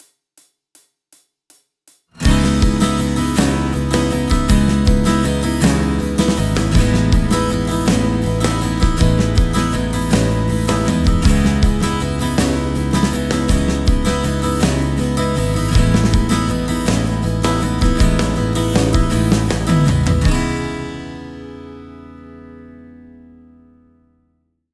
ギター：Gibson J45
マイクプリ：SSL XLogic（EQやコンプは不使用）
録音後には一切処理を行わず、そのまま書き出しています。
6/8拍子 開放コードのレコーディング
LCT 240 PRO バンドミックスサンプル
2/3インチのLCT 240 PROは小さめのダイアフラムらしくスピード感や高域の伸びの美しさが特徴。